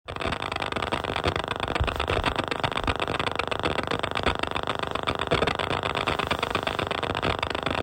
My KRK VXT8’s have a very noticeable crackle (I’ve uploaded a recording).
This noise occurs when there is no audio lead connected.